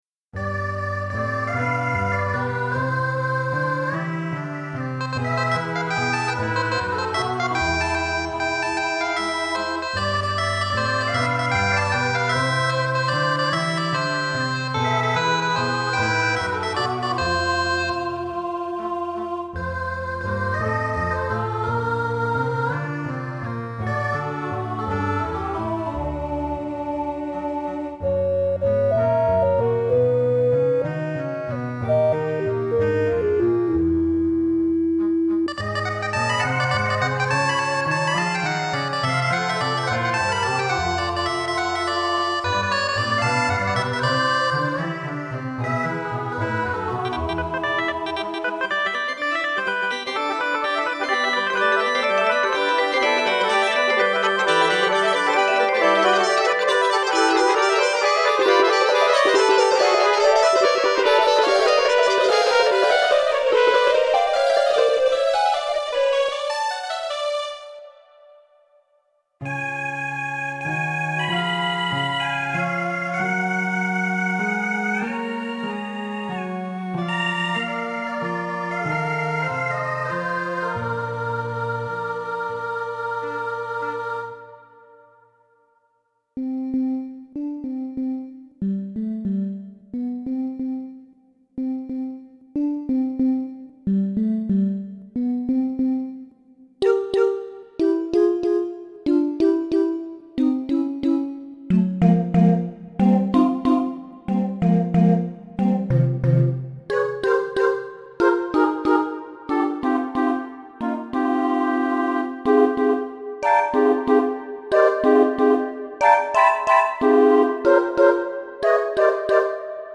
Here's an 'alternative' Christmas piece which I made in 1998 for the Cadenza MIDI Diary!
CHRISTMAS MUSIC